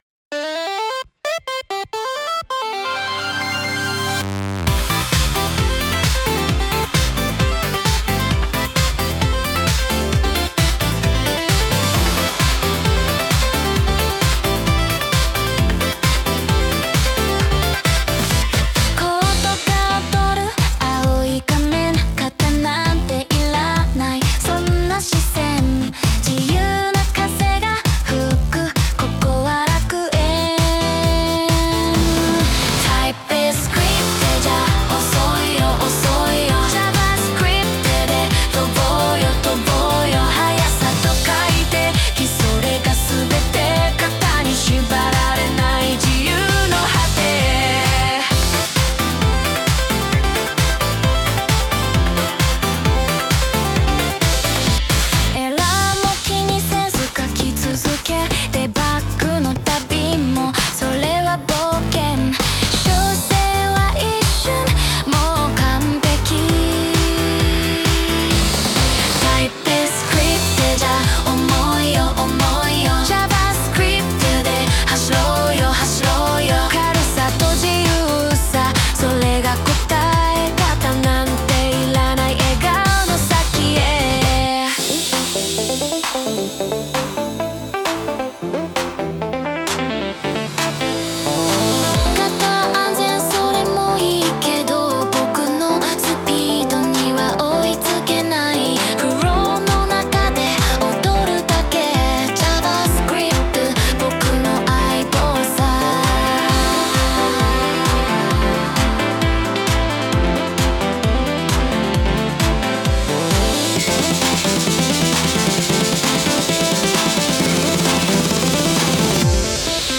文章からAI作曲、MUSICAPI.ORGを使ってサービスに曲を加えよう！
上記、AIにより作詞されたものです。